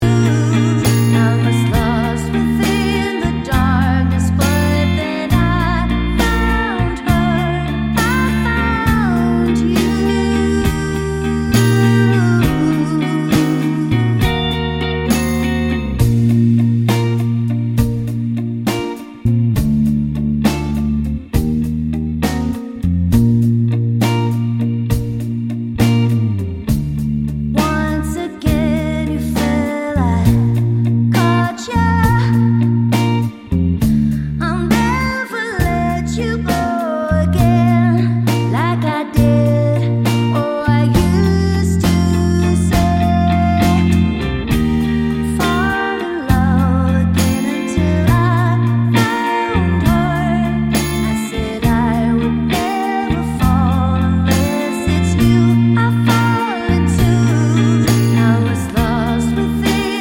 With Female Harmony Pop (2020s) 2:55 Buy £1.50